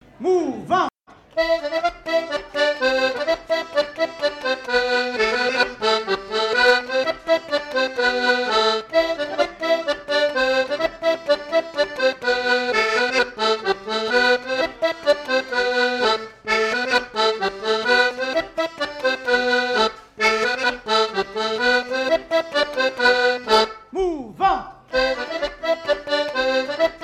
Usage d'après l'informateur gestuel : danse ;
Fête de l'accordéon
Catégorie Pièce musicale inédite